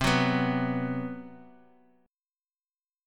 B5/C chord
B-5th-C-x,3,4,4,x,x.m4a